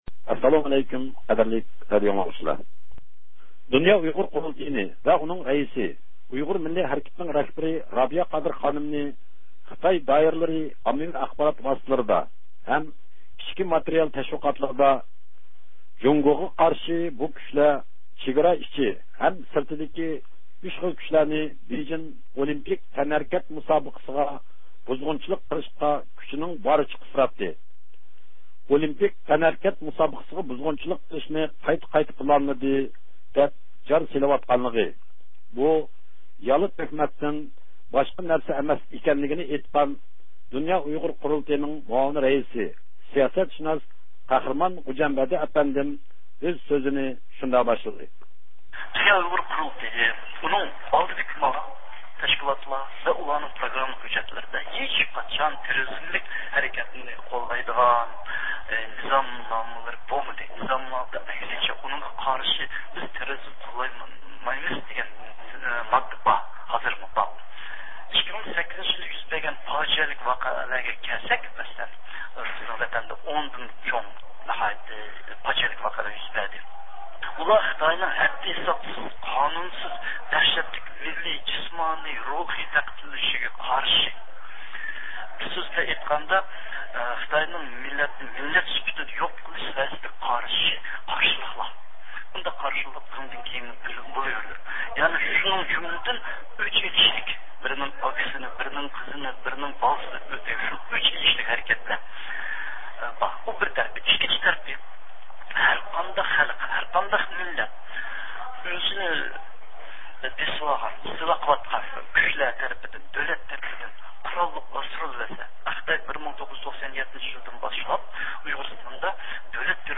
سۆھبىتىنىڭ داۋامىنى ئاڭلايسىلەر.